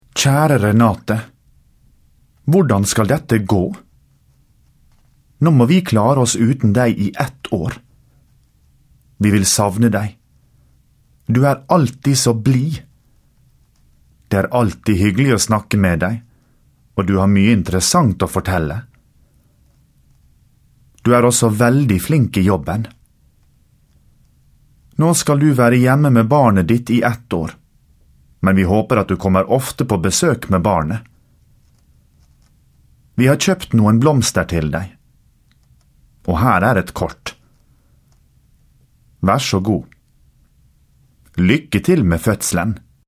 Tale Jeg kan holde en tale.
Egentale A2 Spra?khandling 9